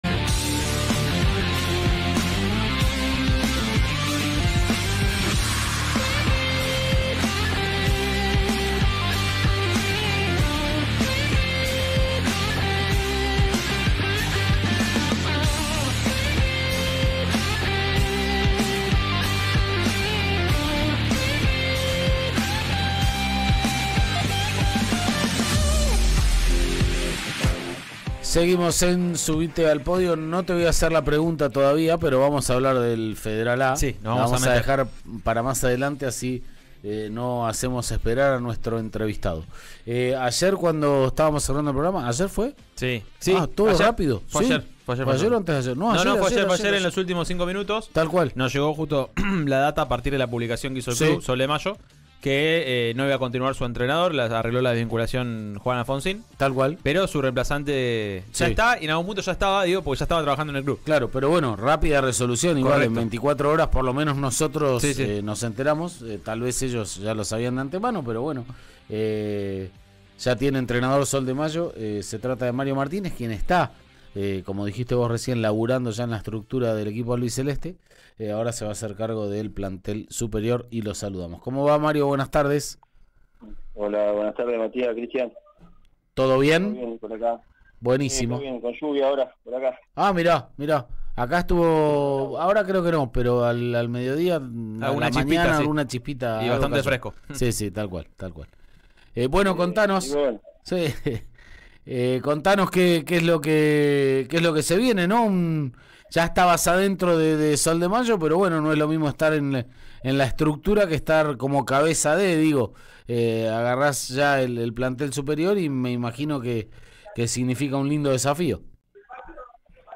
en diálogo con «Subite al Podio» de Río Negro Radio.